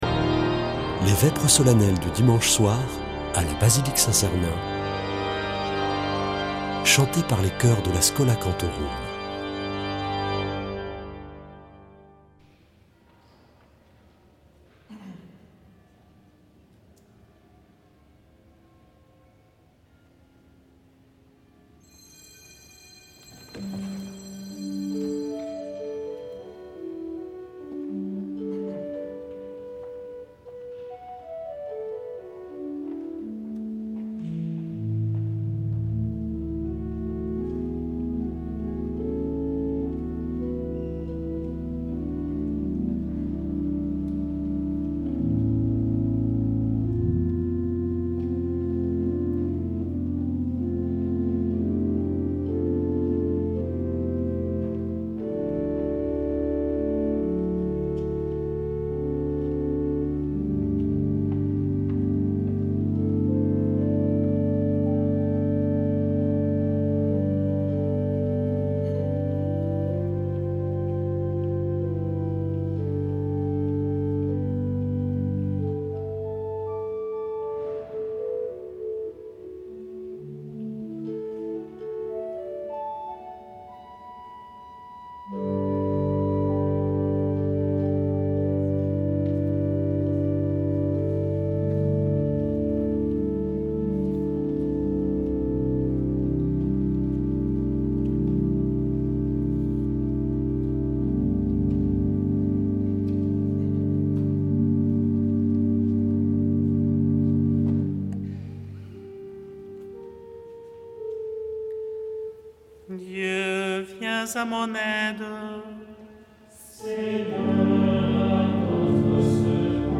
Vêpres de Saint Sernin du 23 juin
Une émission présentée par Schola Saint Sernin Chanteurs